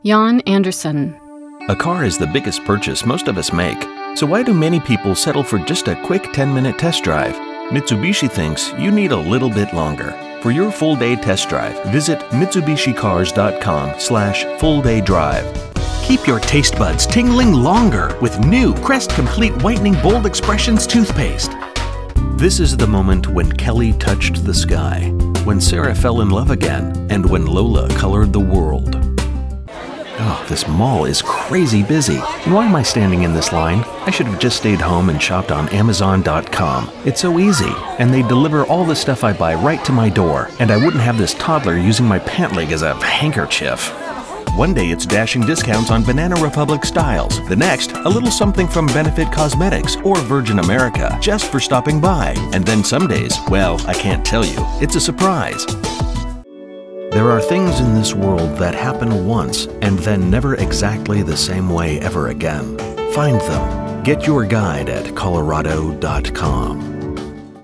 Sprechprobe: Werbung (Muttersprache):
Inviting and friendly, relaxed yet engaging, authoritative, energetic and sincere.